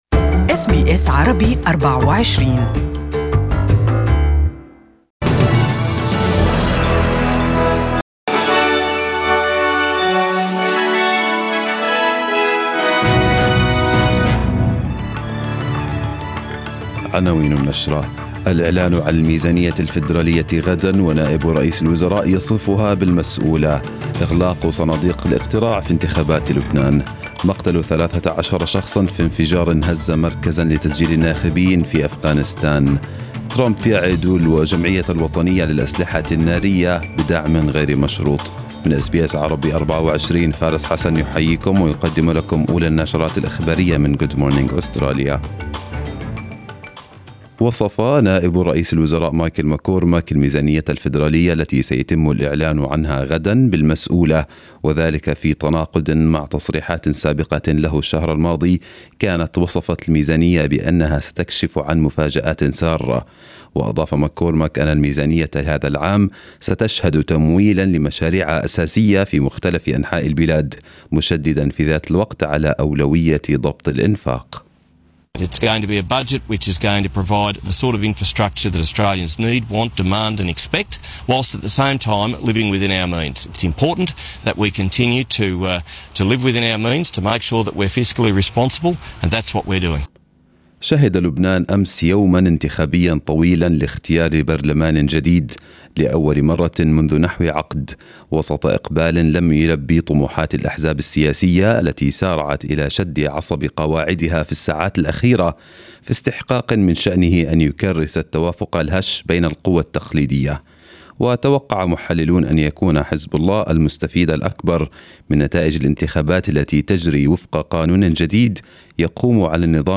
Arabic News Bulletin 07/05/2018